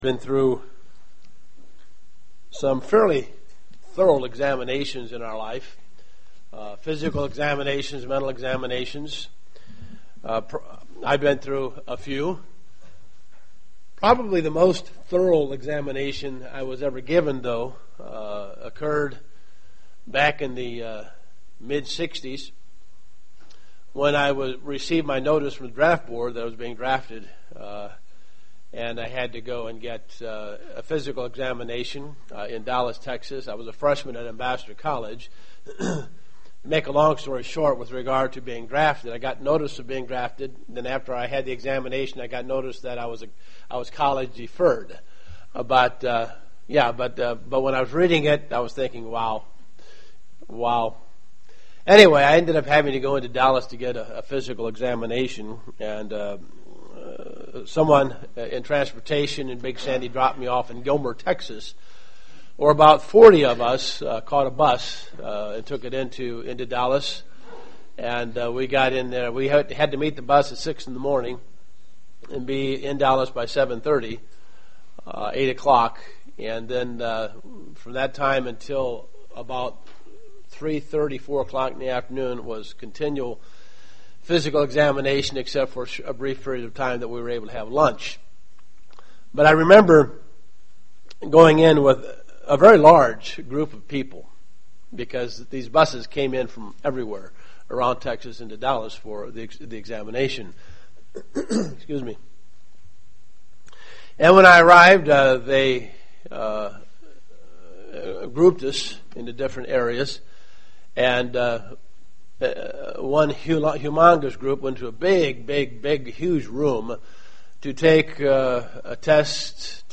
In preparation for Passover and the Days of Unleavened bread one must examine himself. Discover how that process works in this sermon!